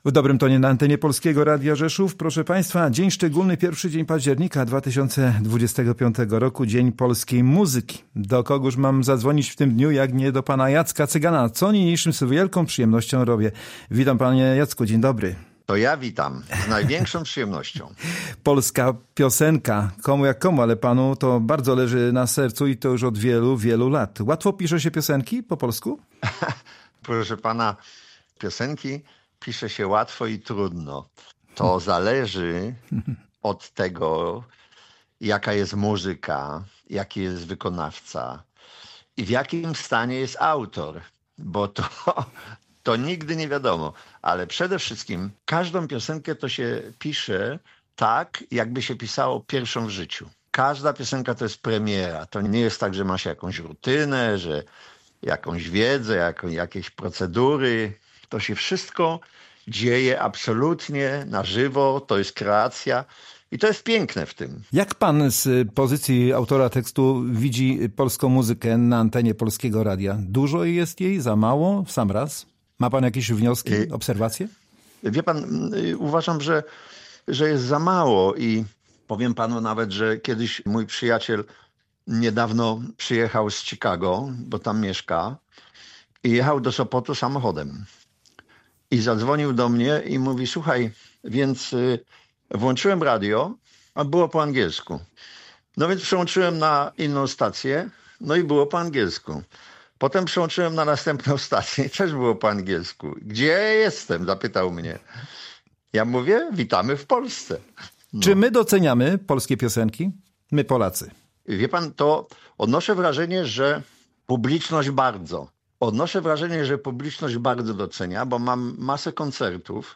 Z tej okazji znany autor tekstów piosenek Jacek Cygan był gościem audycji W dobrym tonie.